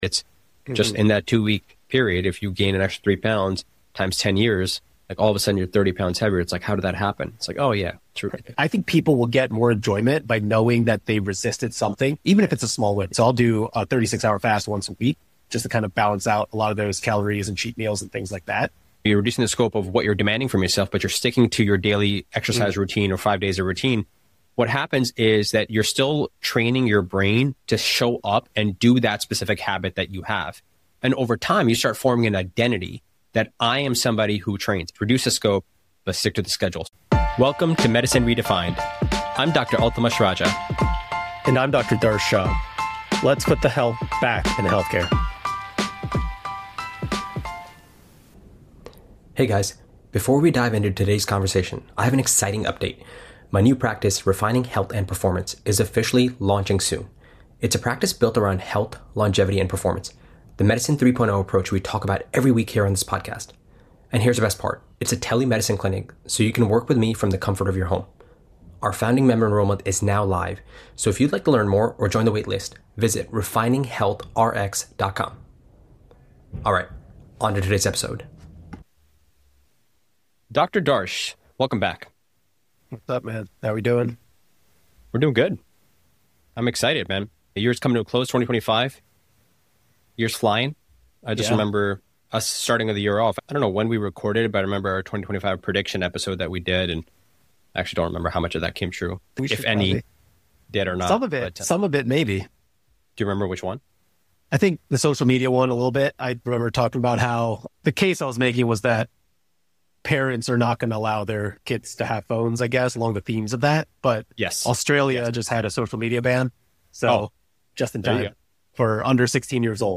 A practical conversation on building health that actually lasts.